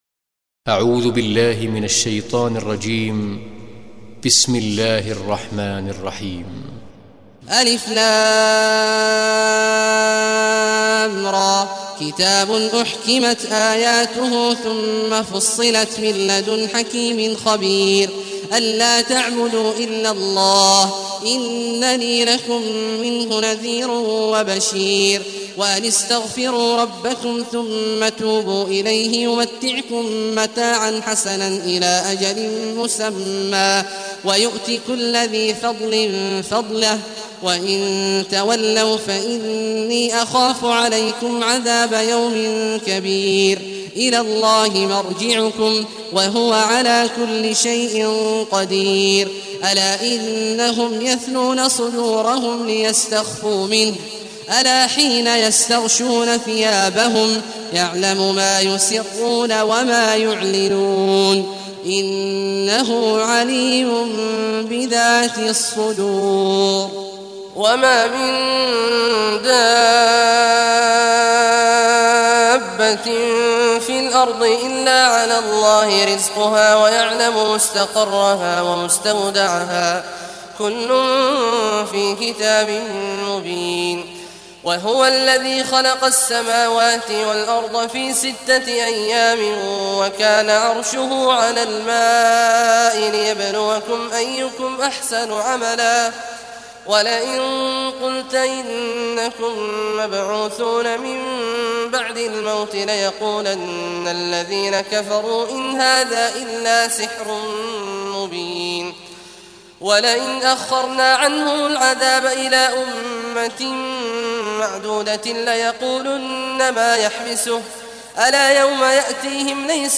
Surah Hud Recitation by Sheikh Abdullah Al Juhany
Surah Hud, listen or play online mp3 tilawat / recitation in Arabic in the beautiful voice of Sheikh Abdullah Awad al Juhany.
11-surah-hud.mp3